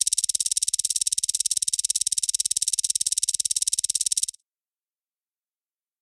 Counter_9.ogg